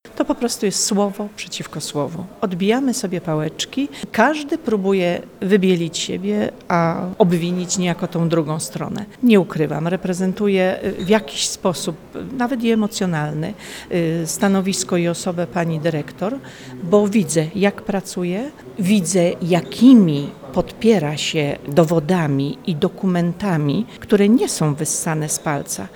Mówi radna klubu PiS Maria Orłowska.